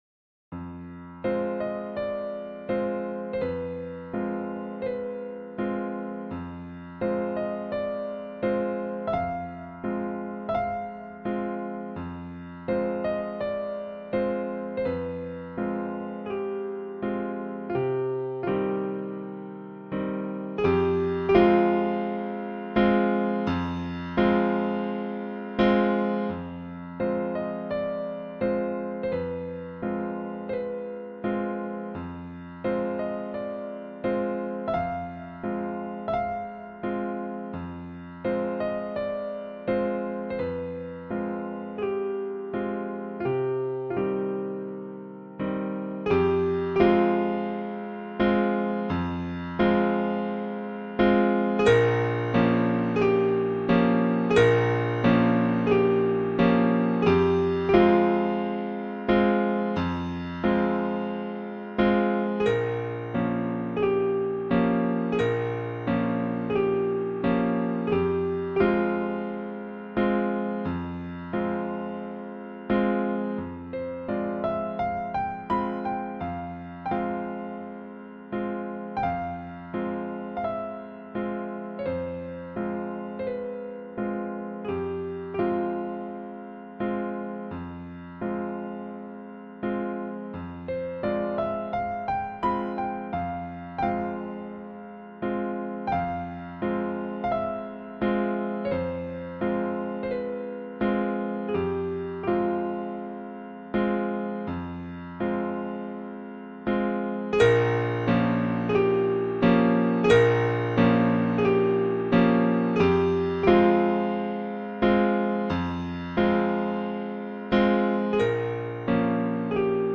original Piano versions